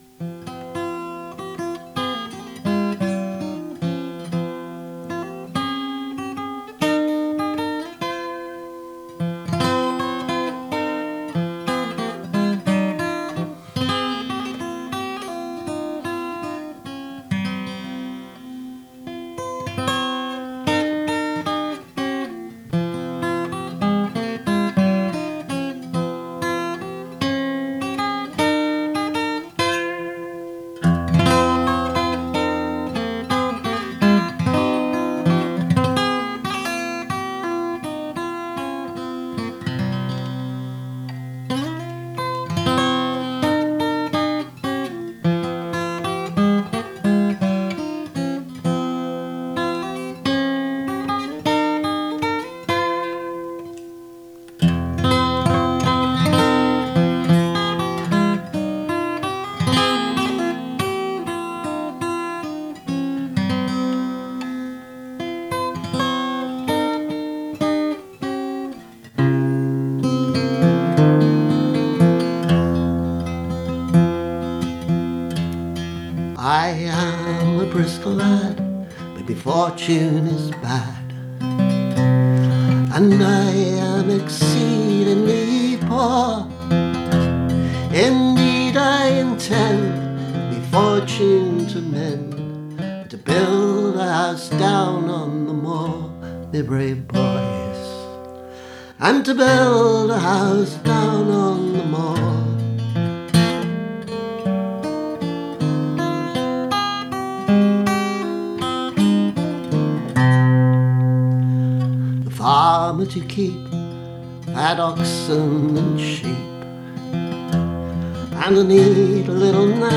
Even more recently, when the Dorset song came up in conversation in a Facebook group frequented by Cornish songwriters, it occurred to me that a somewhat similar guitar part would work quite well with it.
Same version, but tidied and remastered.